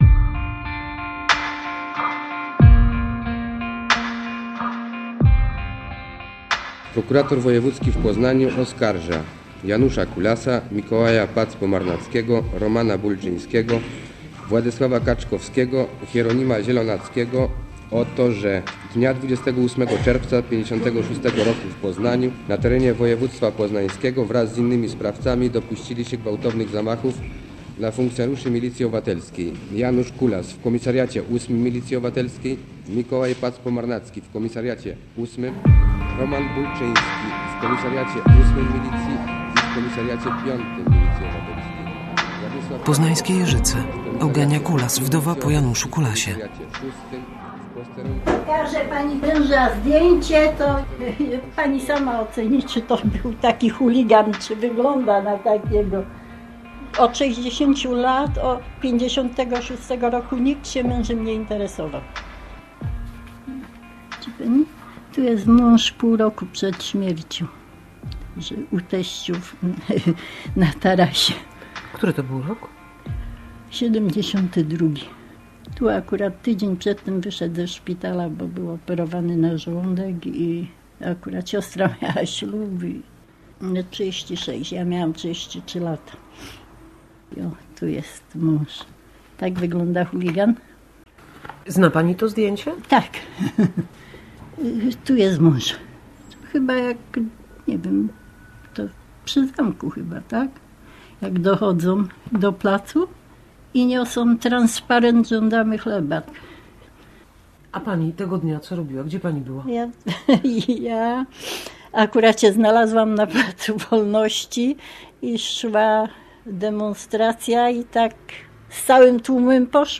Przywracanie pamięci - reportaż